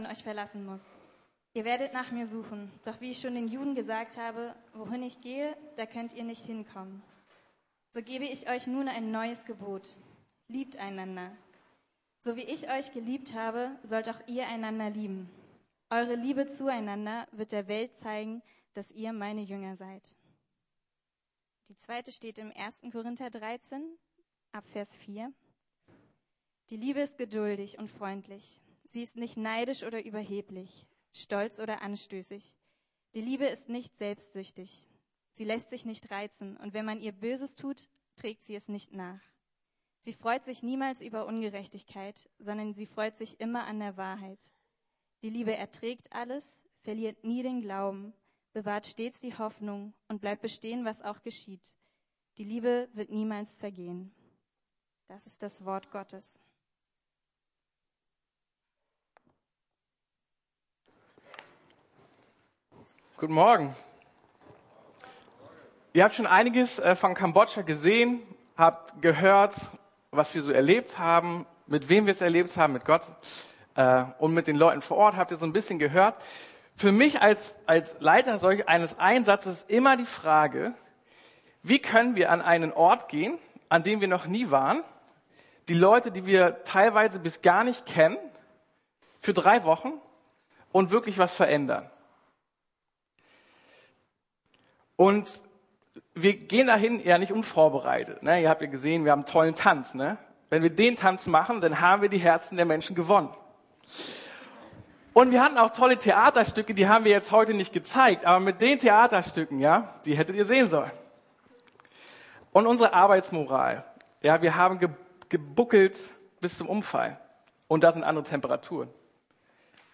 Jüngerschaft heißt Liebestraining ~ Predigten der LUKAS GEMEINDE Podcast